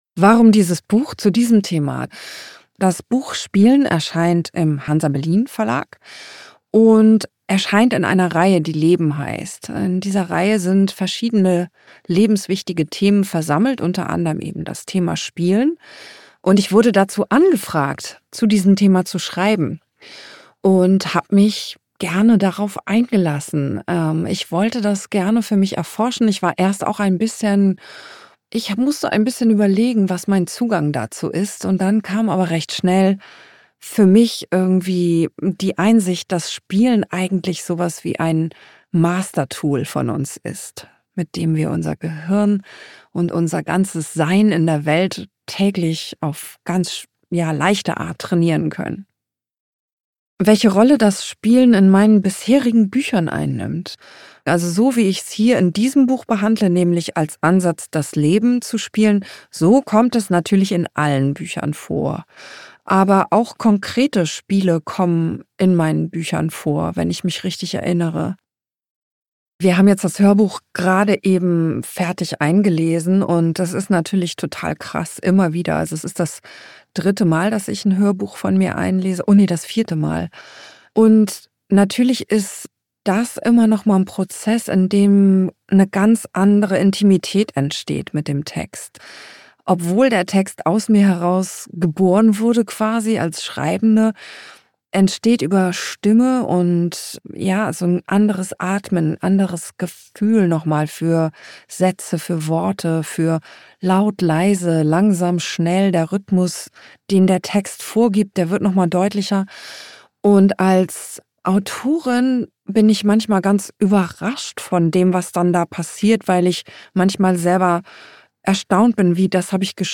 Karen_Koehler_Interview_Spielen.mp3